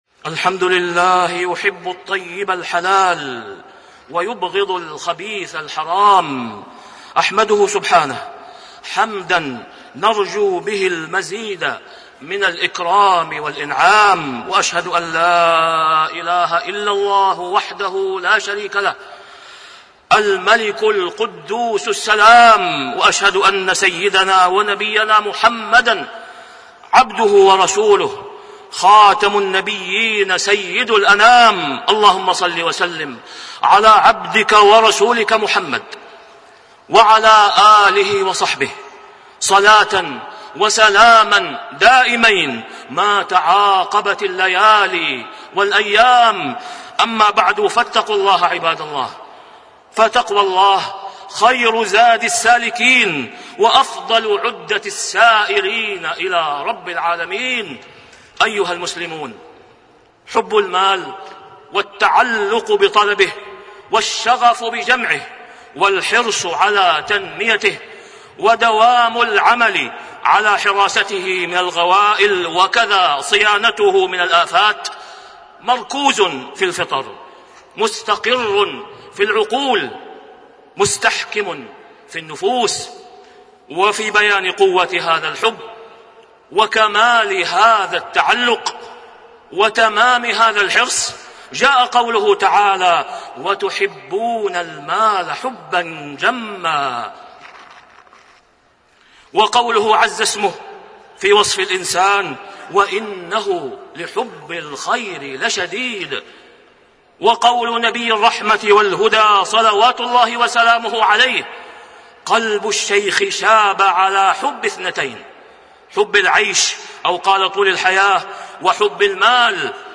تاريخ النشر ٢١ جمادى الأولى ١٤٣٣ هـ المكان: المسجد الحرام الشيخ: فضيلة الشيخ د. أسامة بن عبدالله خياط فضيلة الشيخ د. أسامة بن عبدالله خياط التوبة قبل فوات الأوان